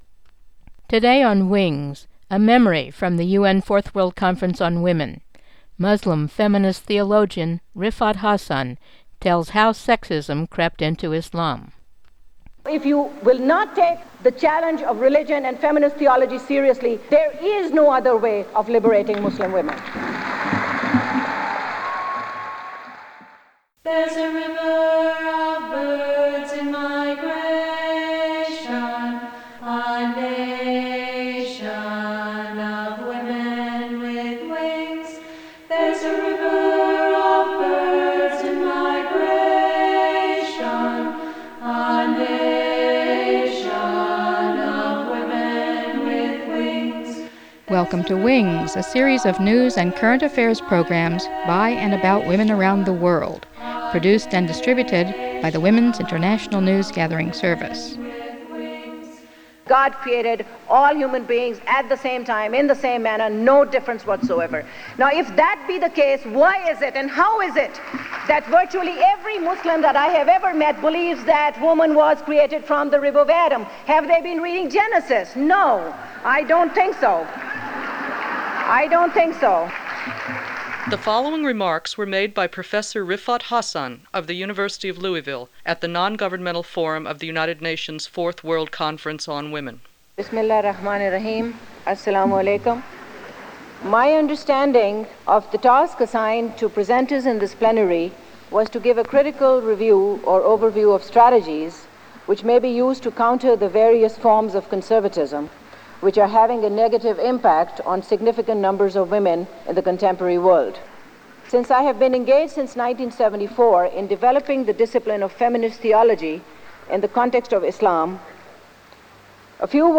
#35-25 Women’s Equality in Islam – Speech by Islamic theologian Riffat Hassan
Riffat Hassan (born 1943) is a Pakistani-American theologian and a leading Islamic feminist scholar of the Qur’an. In this speech recorded at the 4th UN World Conference on Women in Huairou, China, in 1995, she explains that in the Koran women and men are considered created as equals; but Islamic commentators picked up the concept from other religions of women being created from a crooked rib, thus starting a trend for considering women subordinate to men across the Islamic world.